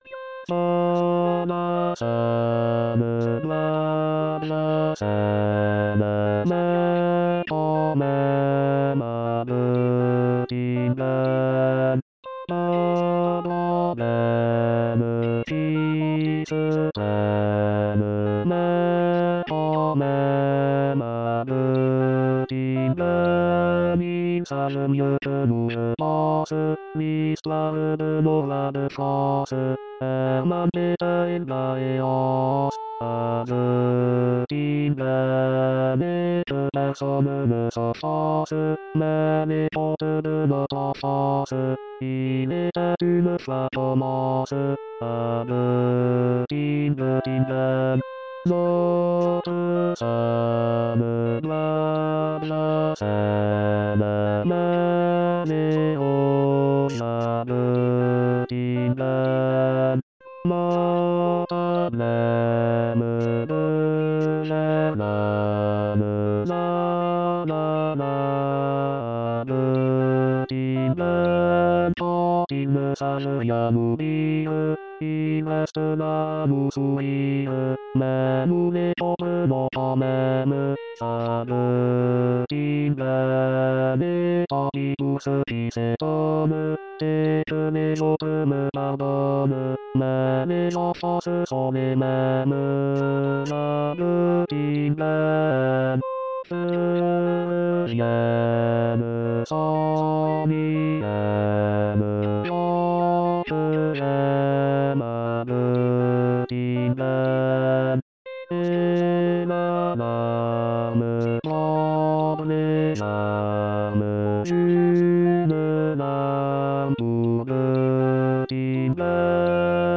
Tenor/Basses (.mp3)